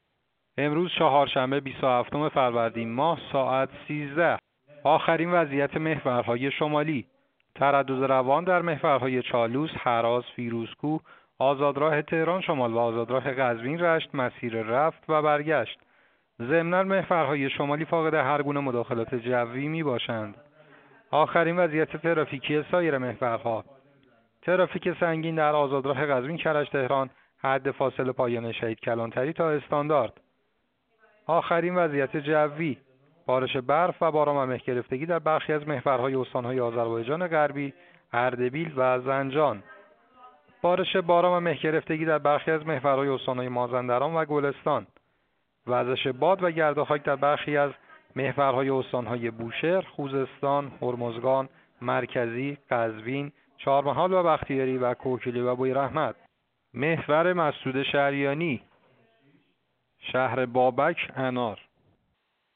گزارش رادیو اینترنتی از آخرین وضعیت ترافیکی جاده‌ها ساعت ۱۳ بیست و هفتم فروردین؛